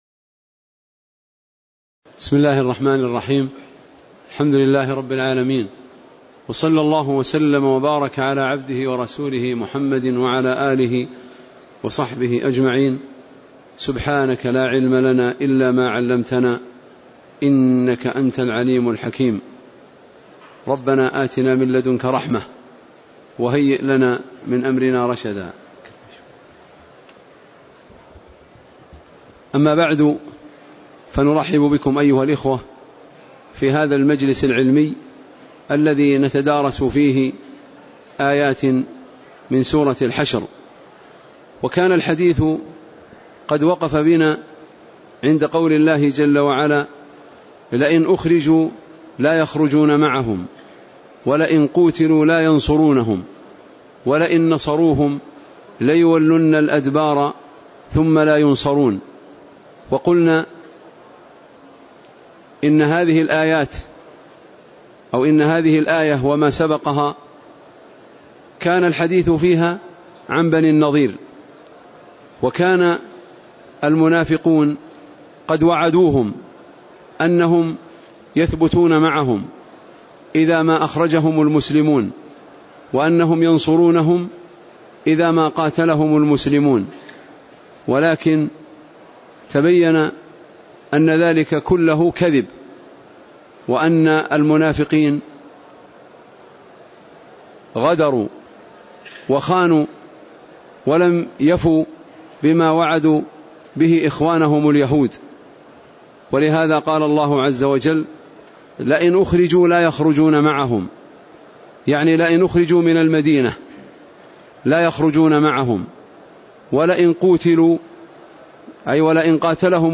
تاريخ النشر ٧ رجب ١٤٣٨ هـ المكان: المسجد النبوي الشيخ